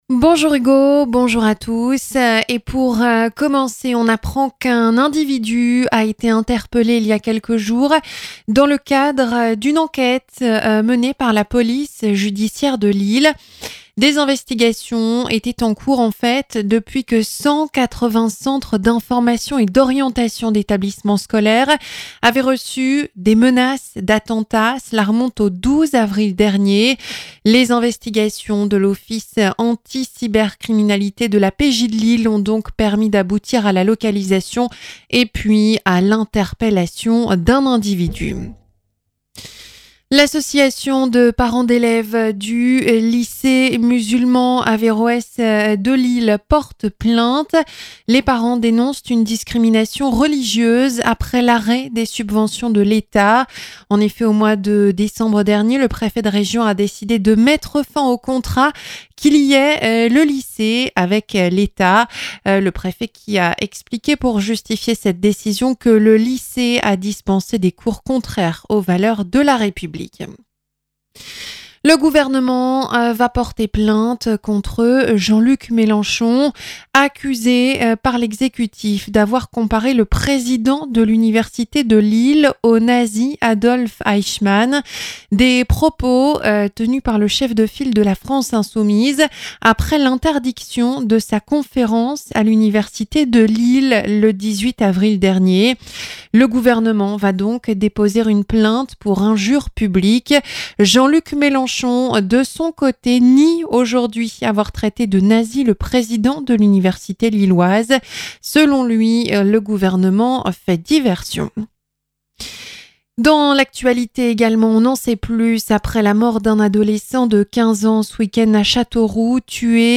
Journal 12h - Menaces attentat : un individu interpellé après enquête de la PJ de Lille